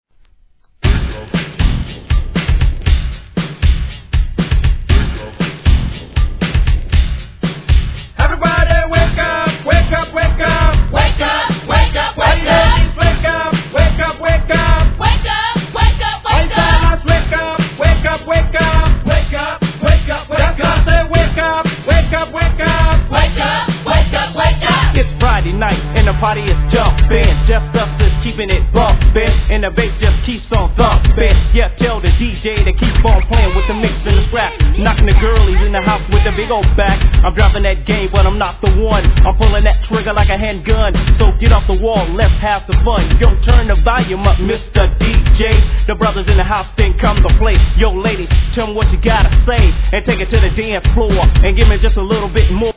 G-RAP/WEST COAST/SOUTH
歯切れあるRAPとキャッチーなフック